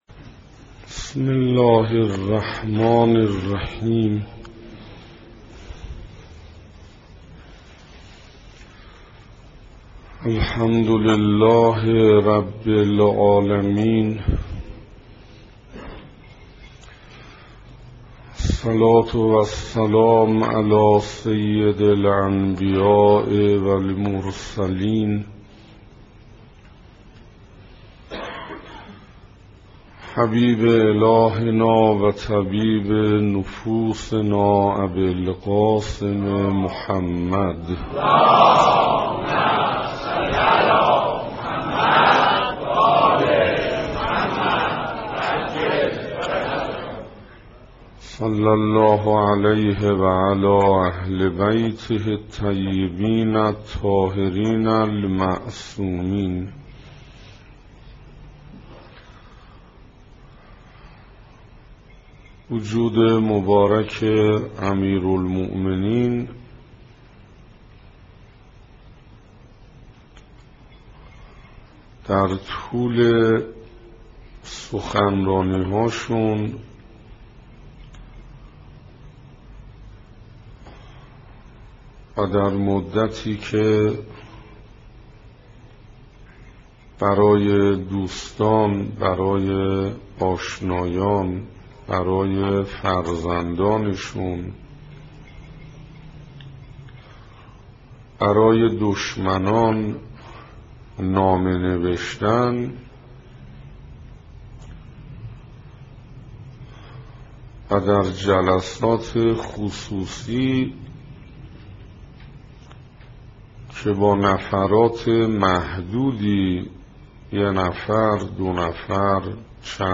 سخنراني بيست و يکم
صفحه اصلی فهرست سخنرانی ها نگاهي به آيات قرآن (2) سخنراني بيست و يکم (تهران بیت الزهرا (س)) رمضان1427 ه.ق - مهر1385 ه.ش دانلود متاسفم..